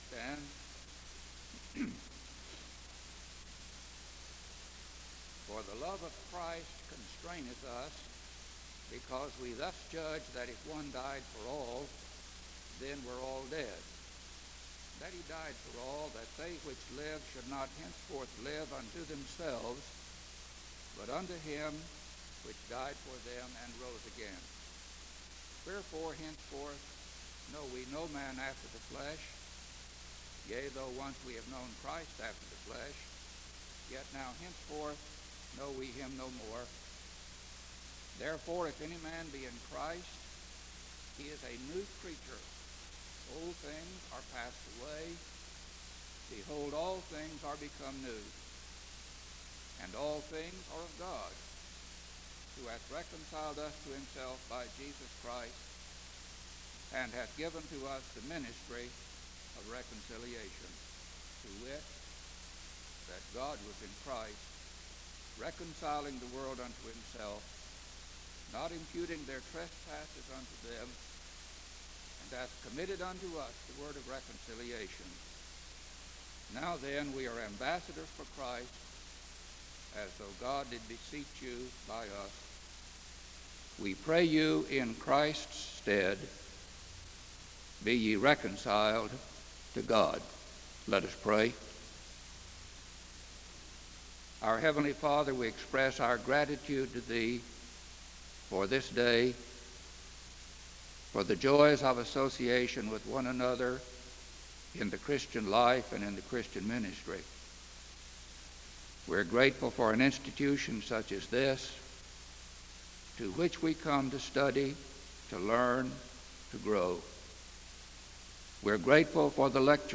The service begins with a Scripture reading and a moment of prayer (0:00-2:51).